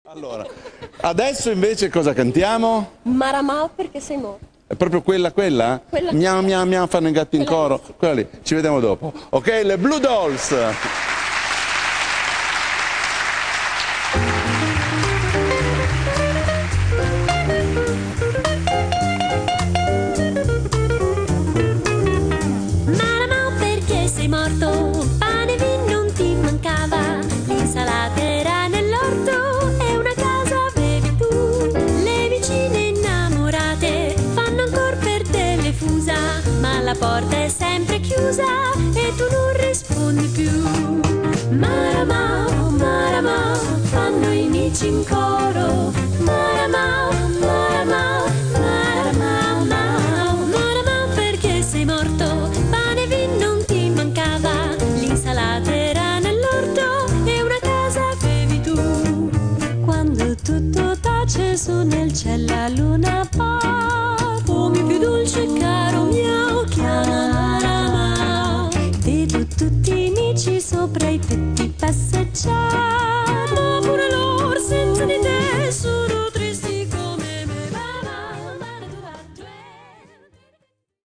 Soprano
Alto
Piano
Guitar
Double Bass
Drums